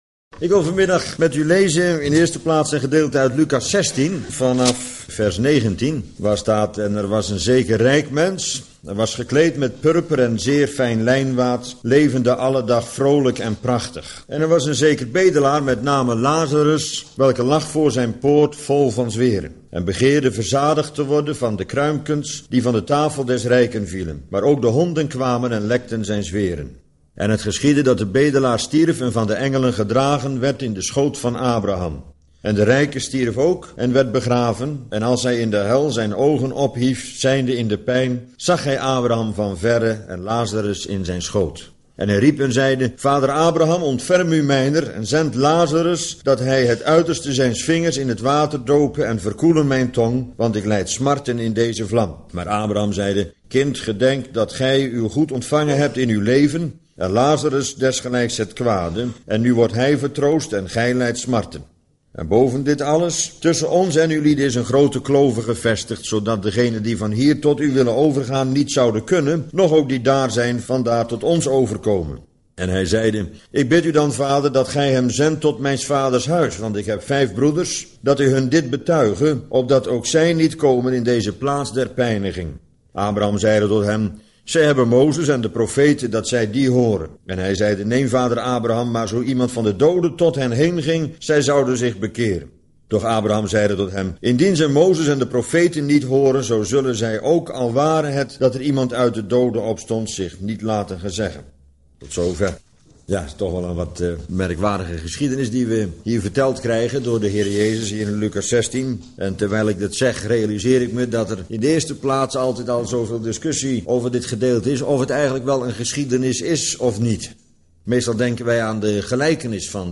Bijbelstudie lezing onderwerp: De rijke man en Lazarus (Luk.16)